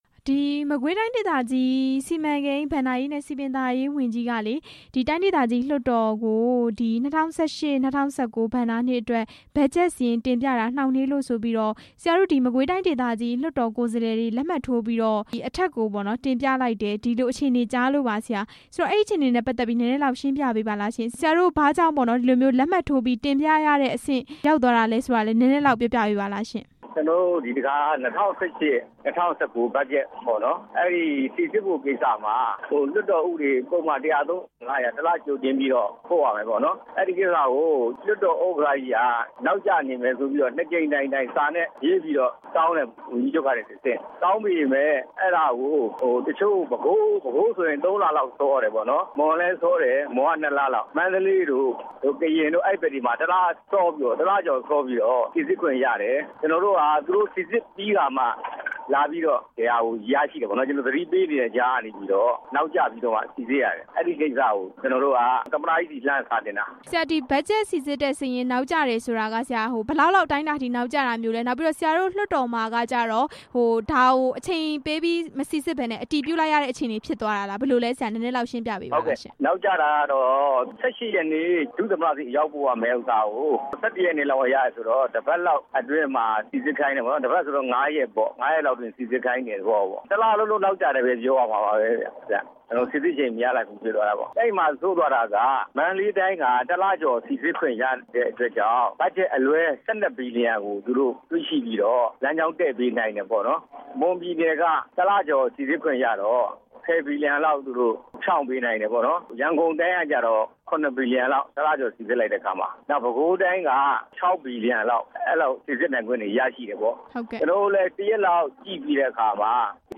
မကွေးဝန်ကြီး တိုင်ကြားခံရတဲ့အကြောင်း မေးမြန်းချက်